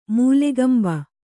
♪ mūlegamba